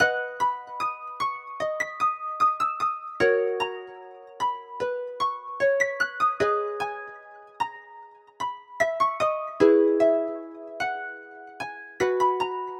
饶舌的钢琴旋律
Tag: 75 bpm Rap Loops Piano Loops 2.15 MB wav Key : Unknown